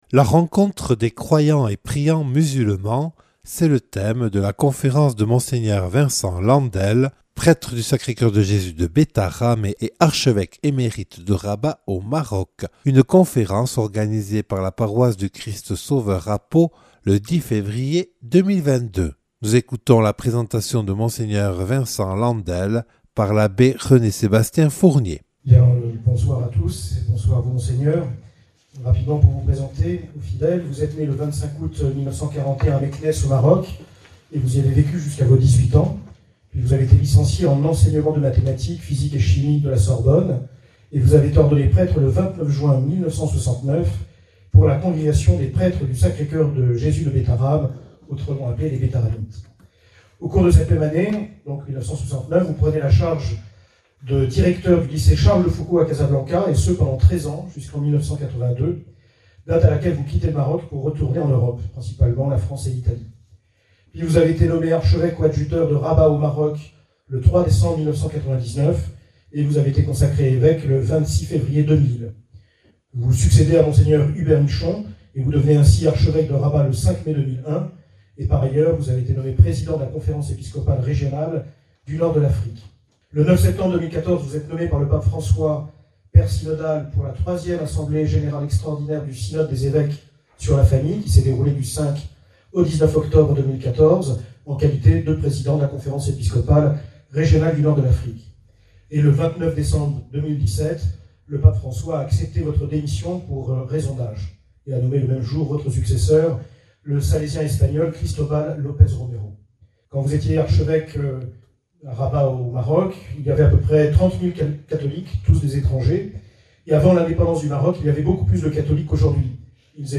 Conférence de Mgr Vincent Landel, scj, archevêque émérite de Rabat au Maroc.
(Enregistrée le 10 février 2022 à Pau lors d’une soirée proposée par la paroisse du Christ Sauveur).